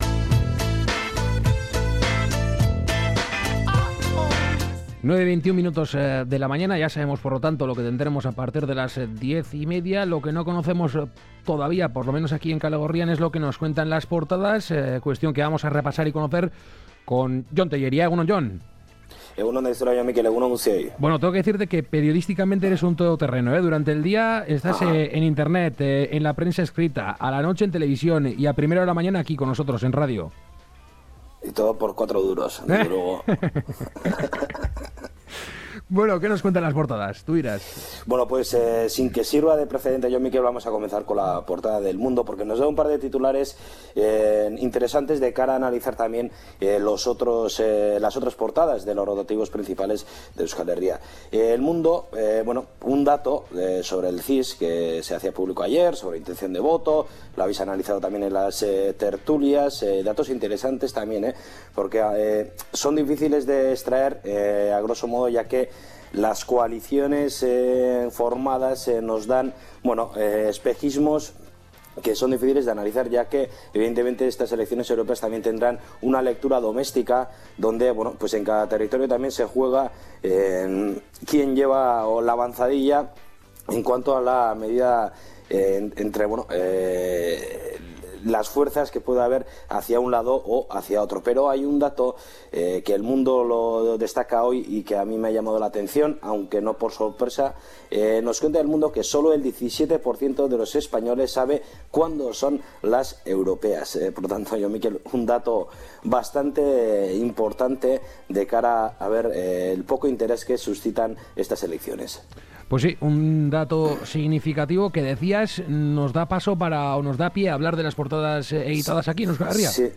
Puedes seguir la lectura de prensa mientras observas las portadas del día.